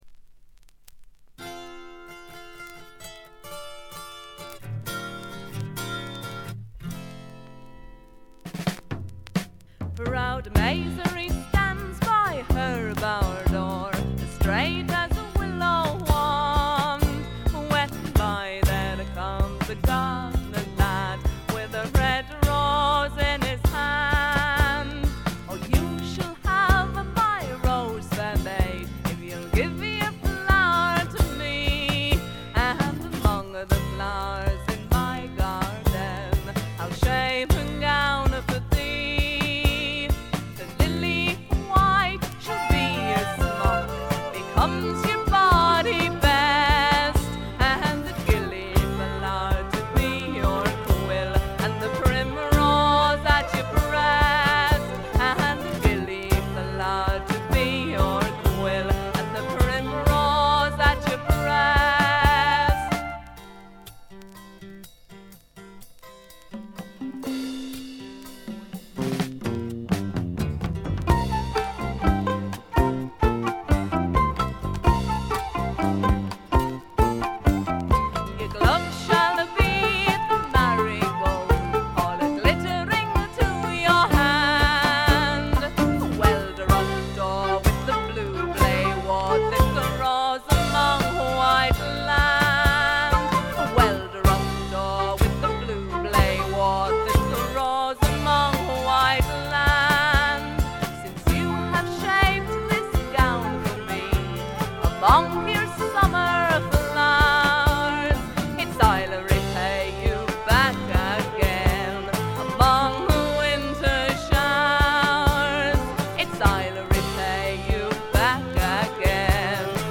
静音部で軽微なチリプチが聴かれますが鑑賞に影響するようなノイズはありません。
ドラムとベースがびしばし決まるウルトラグレートなフォーク・ロックです。
試聴曲は現品からの取り込み音源です。